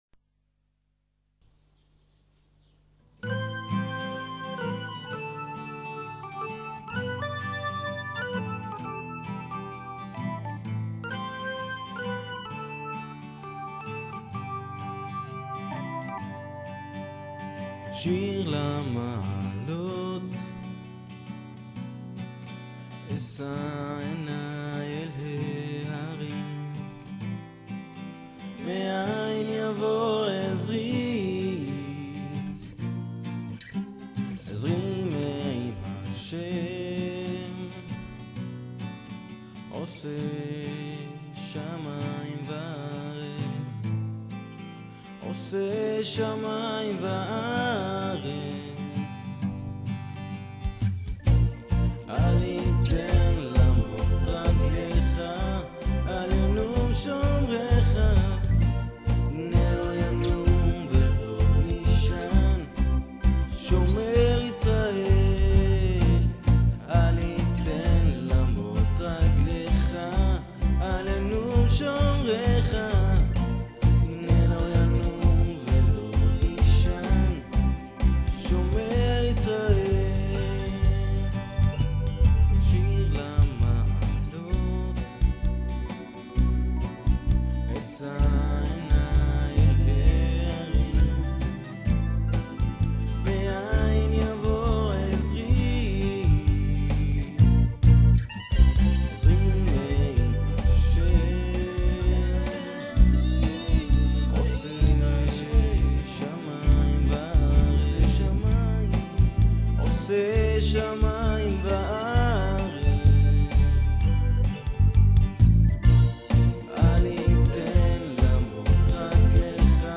היתה באמצע חרקה קטנה של המיקרופון וחוצמזה הכל פשוט יפה!
לחן יפה. סוחף ומעניין.
ניכרת גם הבחירה הנכונה של עוצמות ההקלטה, גם בנגינה וגם בקול - שקט ואחיד.
נשמע ממש כמו כל שיר ברדיו..הוא קליט כזה וממש ממש יפה!